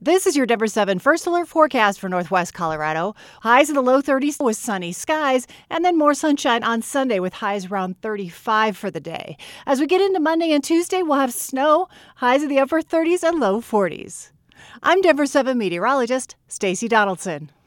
Weather Report